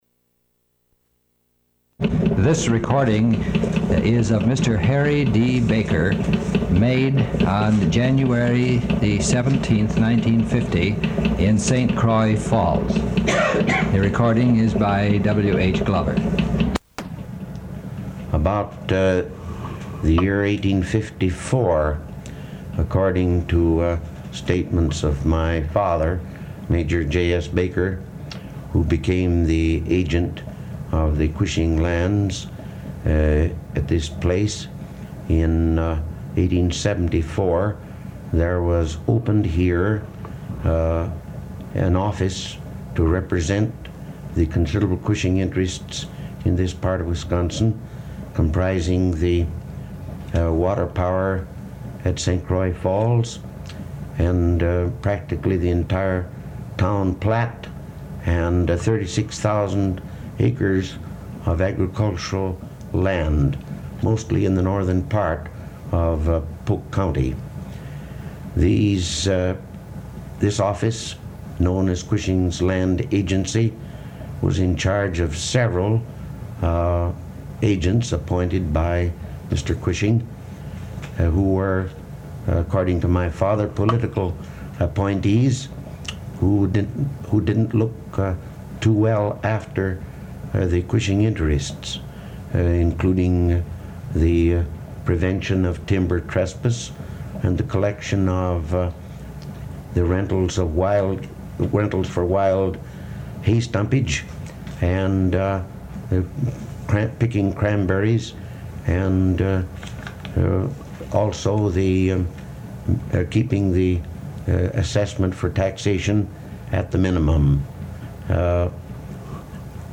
Wisconsin Historical Society Oral History Collections